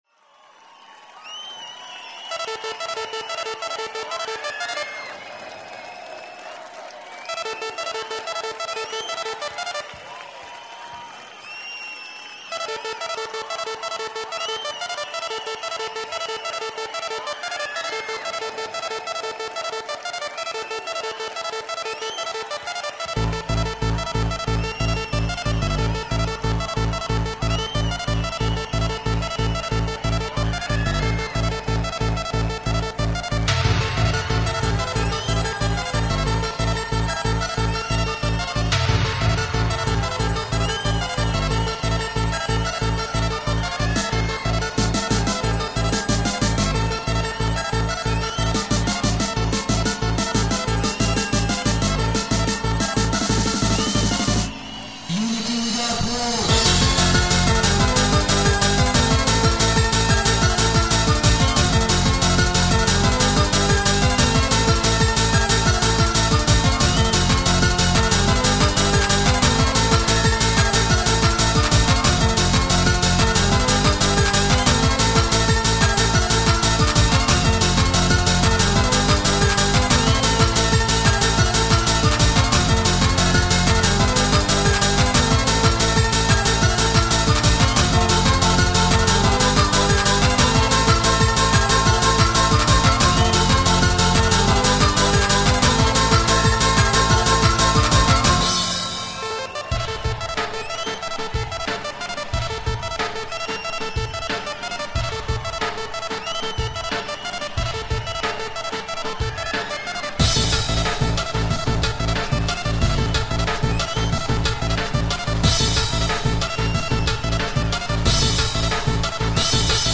• Jakość: 44kHz, Stereo